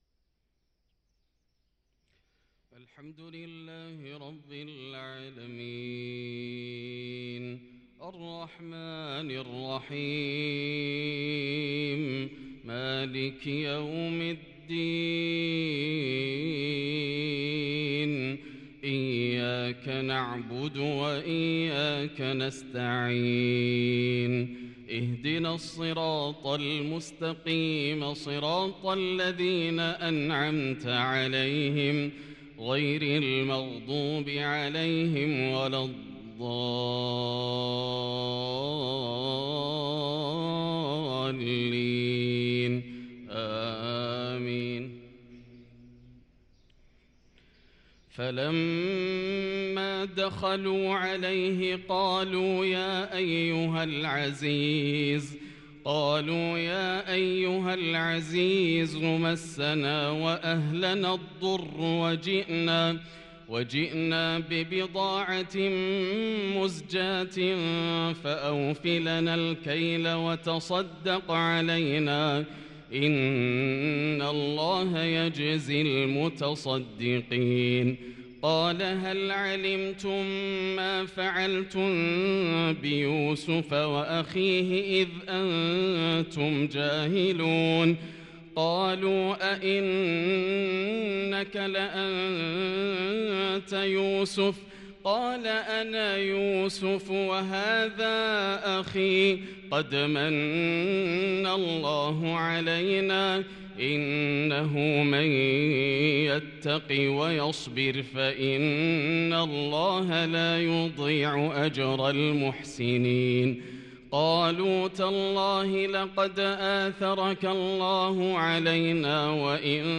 صلاة الفجر للقارئ ياسر الدوسري 7 جمادي الأول 1444 هـ